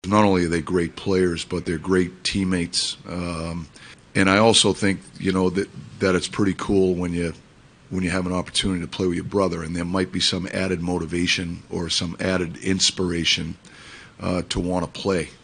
Sullivan says the Tkachuk brothers gave the Americans a spark.